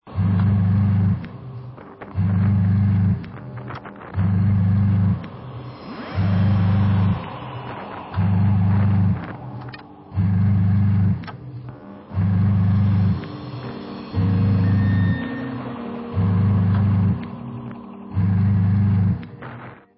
-ULTRA RARE MIND EXPANDING FUZZ FILLED GEMS! -